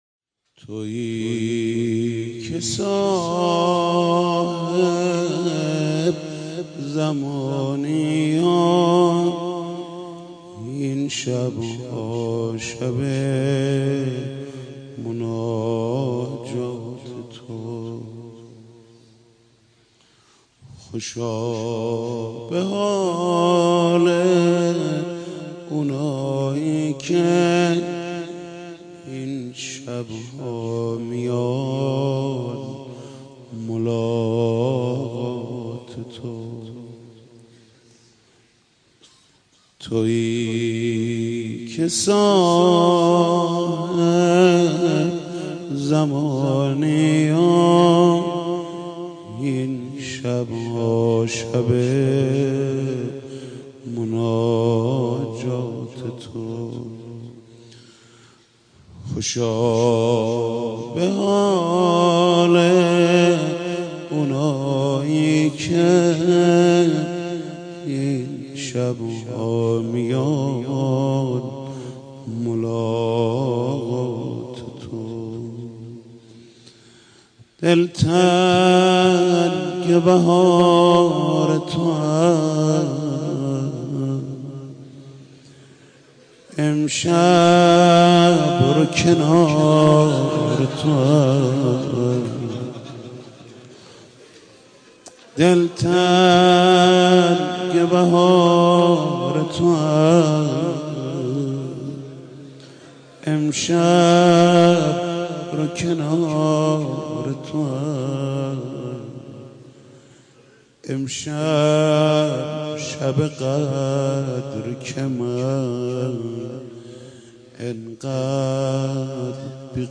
مناجات با امام زمان ع با صدای حاج محمود کریمی -( تویی که صاحب زمانی و این شب ها شب مناجات تو )